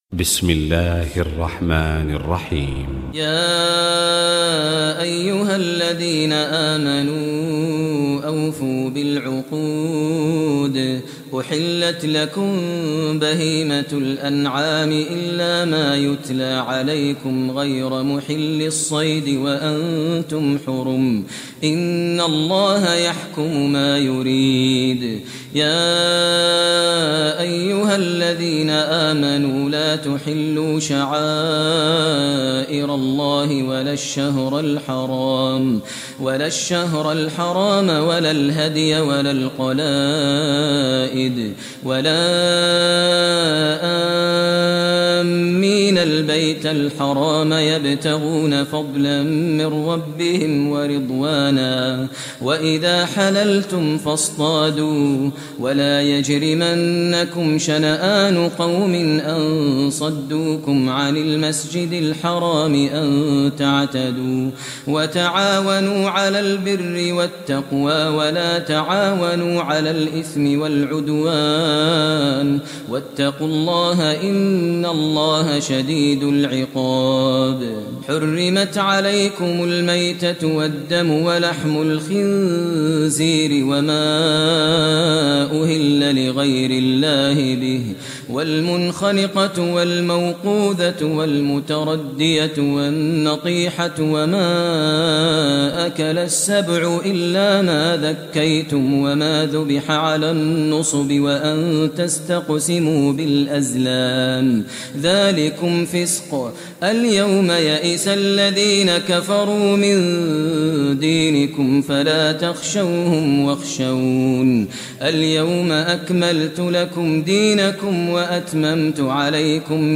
Surah Maida Recitation by Sheikh Maher al Mueaqly
Surah Maida, listen online mp3 tilawat / recitation in Arabic in the voice of Sheikh Maher al Mueaqly.